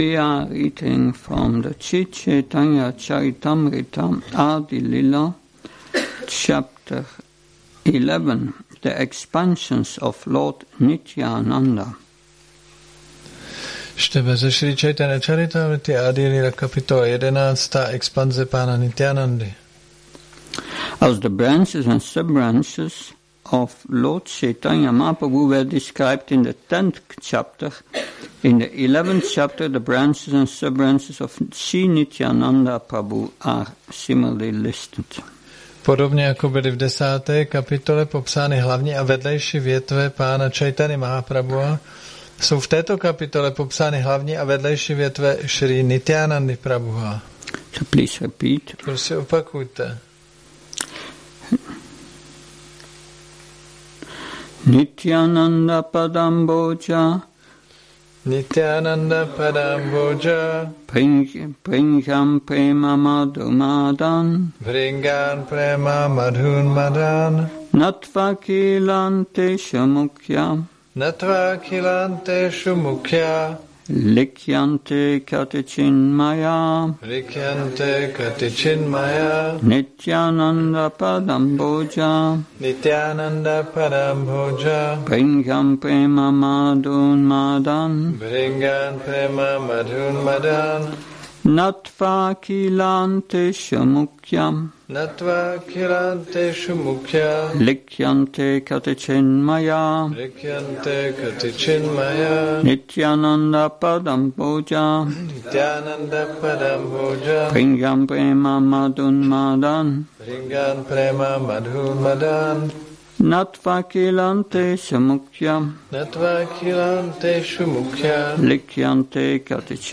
Přednáška CC-ADI-11.1 – Šrí Šrí Nitái Navadvípačandra mandir